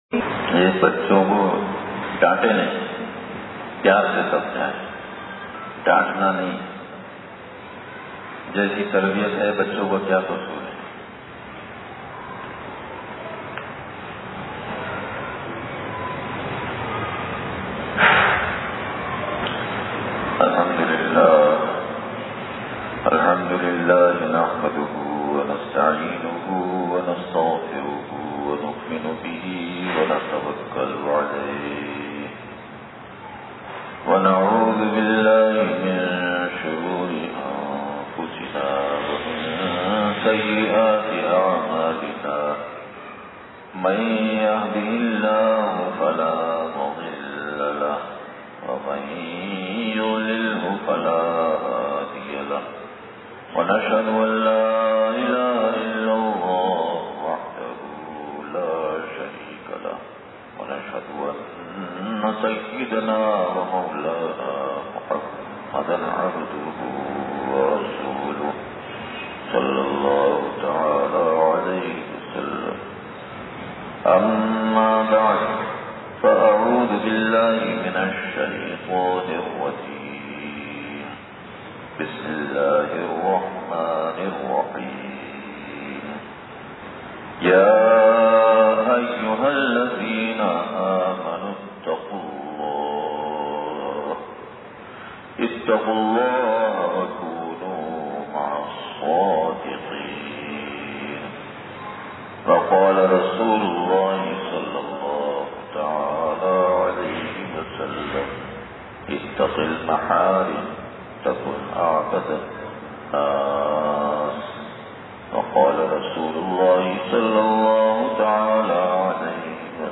بیان بعد نمازِمغرب جامع مسجد فرحت الاسلام صیفل گو ٹھ نیو کراچی